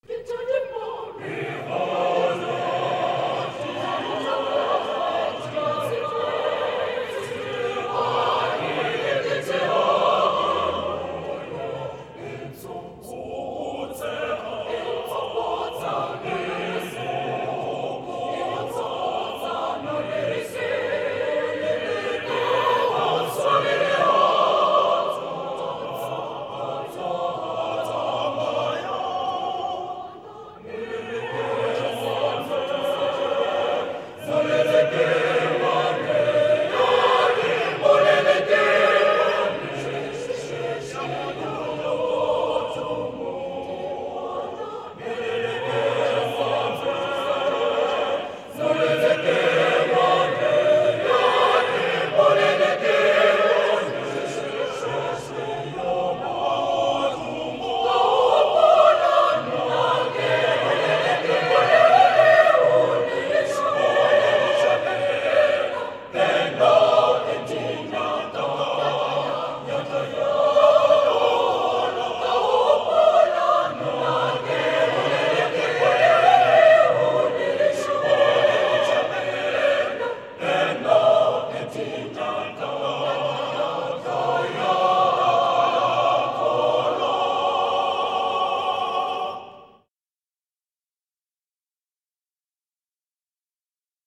In this lyrical 1951 township song, which became a popular South African protest song during apartheid, the narrator tells of his beautiful sister, Molelekeng, who would one day cost him dearly in betrothal expenses.
Type: Live Recording Performers: Massed choirs: sung at the Massed Choir Festival in 2001, digitized by the SABC in 2003, CDT2324.